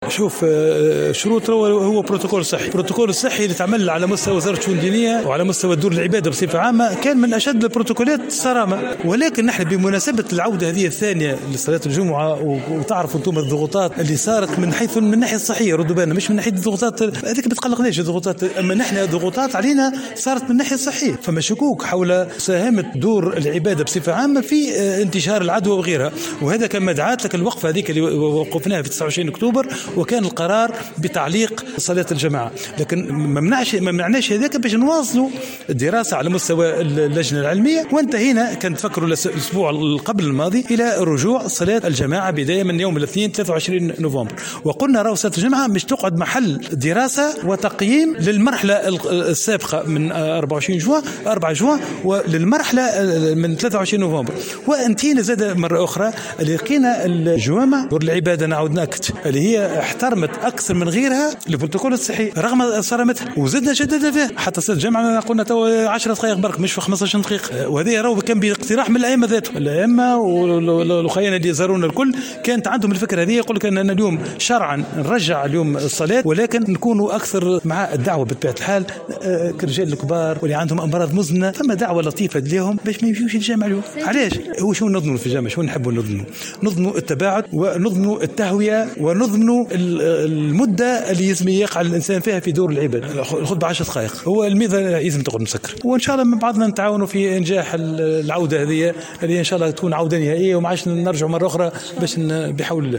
كما بين عظوم في تصريح للجوهرة اف ام عقب ندوة صحفية ان دور العبادة احترمت أكثر من غيرها البروتوكول الصحي وتطبيقه كان بأكثر صرامة .